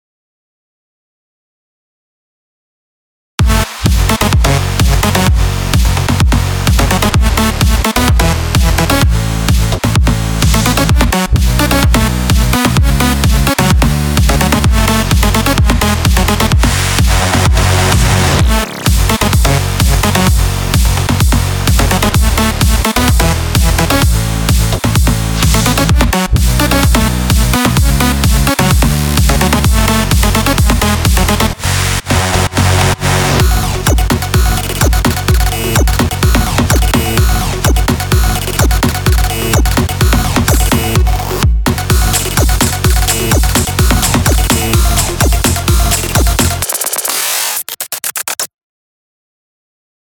זה טראק שבניתי על קורג900
סאונד טוב.